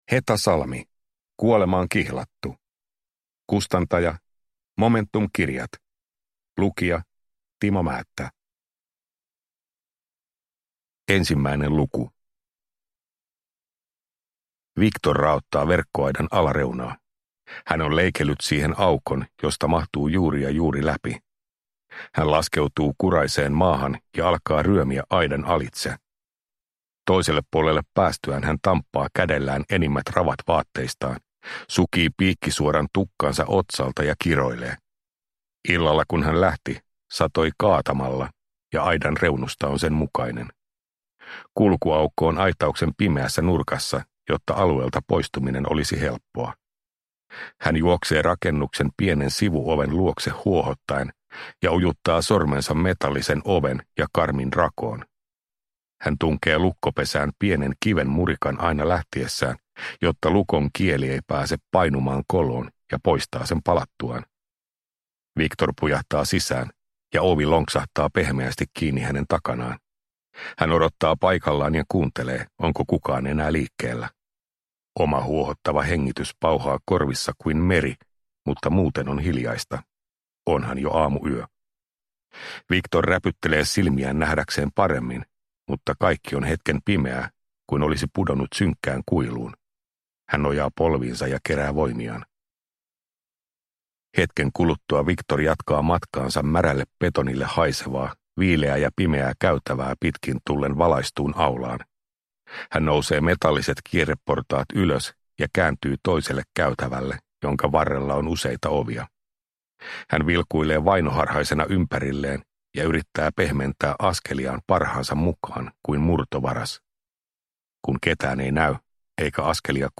Kuolemaan kihlattu (ljudbok) av Heta Salmi